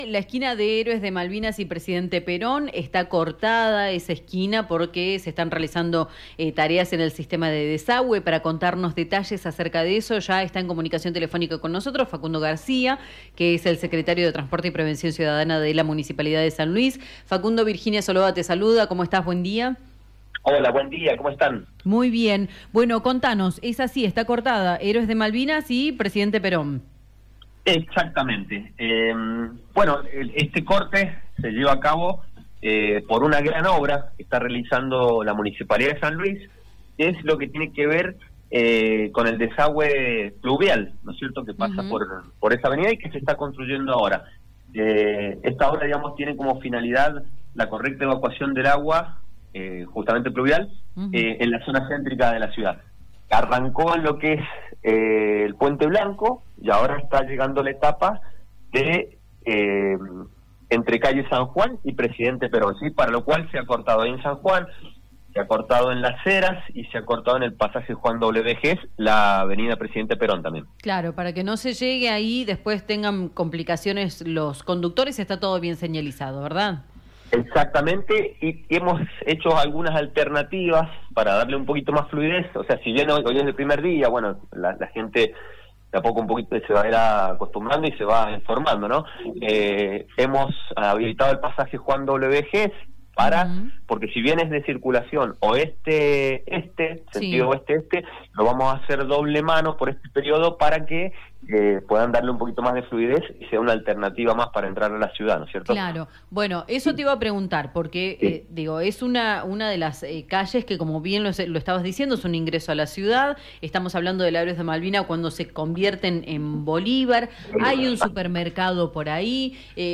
“Este corte se lleva a cabo por una gran obra que esta llevando a cabo la Municipalidad. El objetivo y el fin de esta obra es muy importante para la ciudad, pedimos paciencia porque dificulta el tránsito, pero entendemos que es una mejora para el vecino”, comentó Facundo García, secretario de Transporte y Prevención Ciudadana de la Municipalidad de San Luis, en diálogo con La Red San Luis.